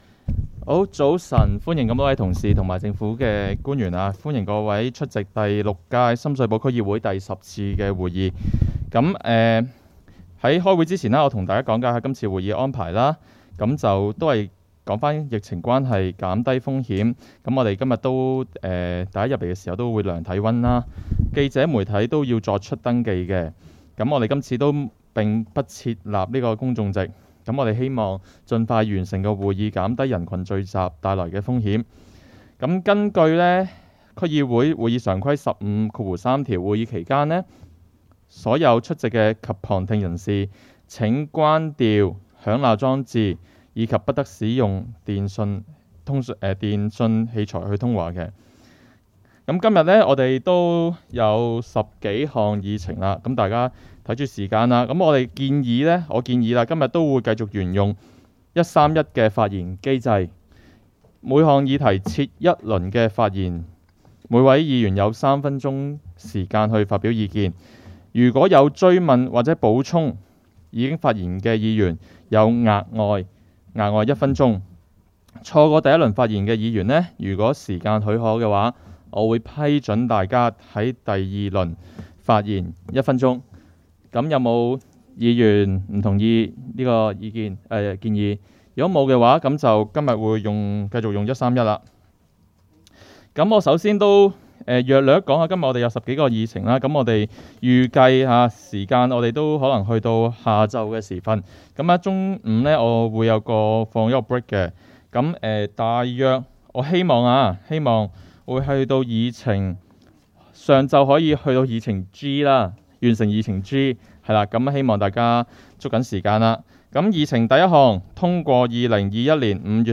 区议会大会的录音记录
深水埗区议会会议室